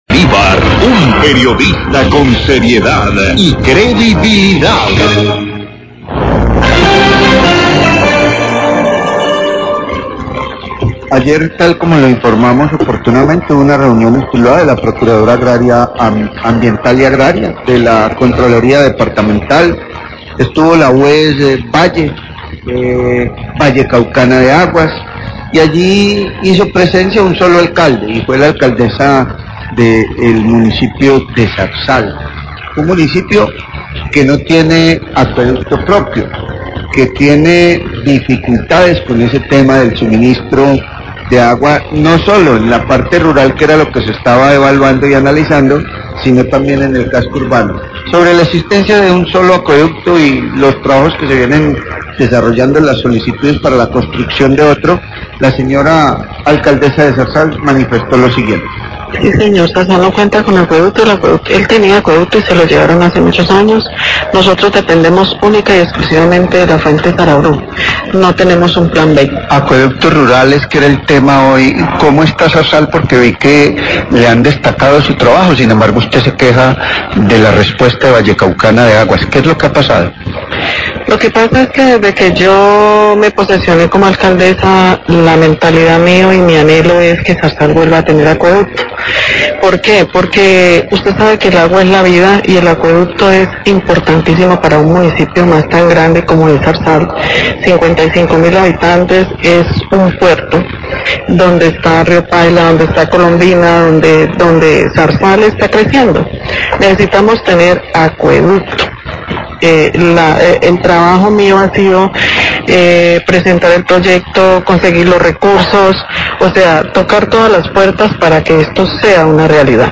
Radio
Procuradora, funcionaria de la contraloría y director de la UES hablan de la problemática y de las medidas que deben tomar los entes encargado para mejorar el servicio prestado y la calidad del líquido.